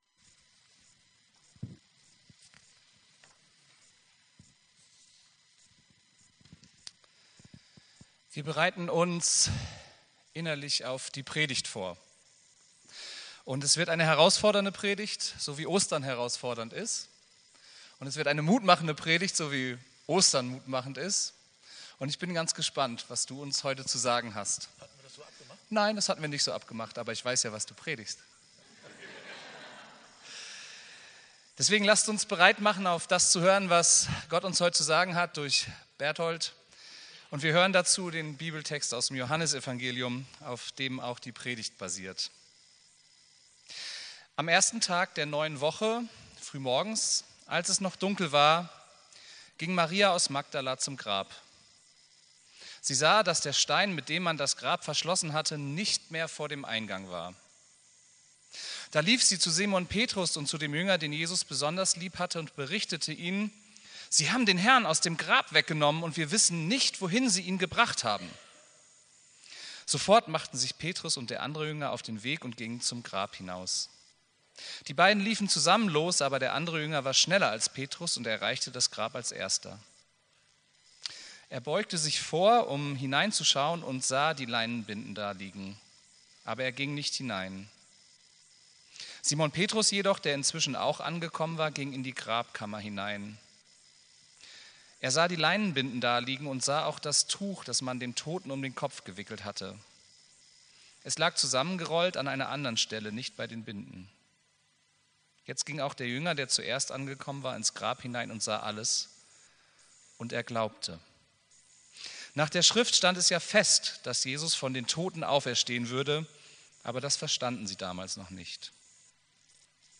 Predigt vom 31.03.2024